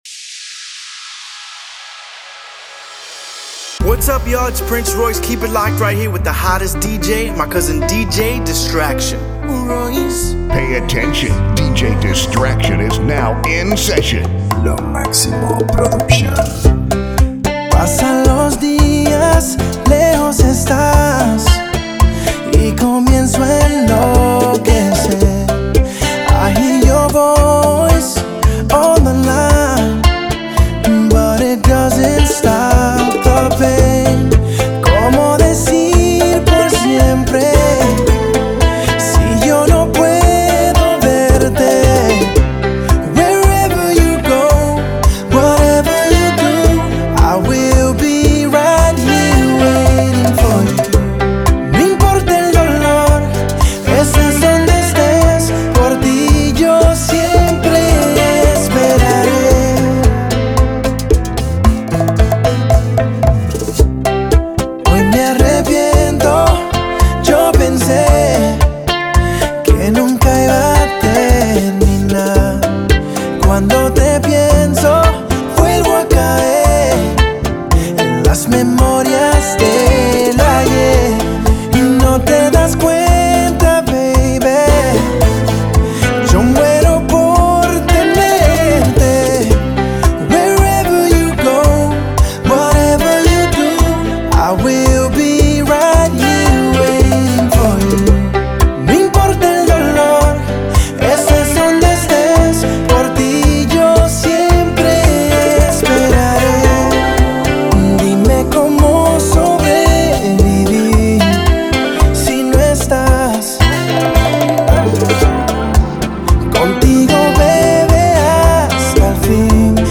smooth bachata vibes